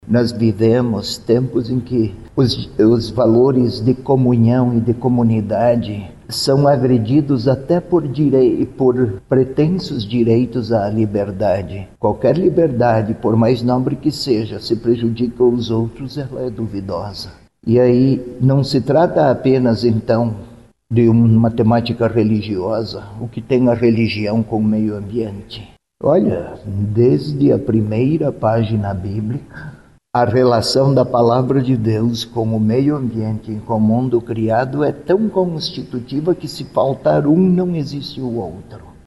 A divulgação deste ano aconteceu durante coletiva de imprensa, na Cúria Metropolitana, na capital.